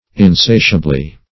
Insatiably \In*sa"tia*bly\, adv.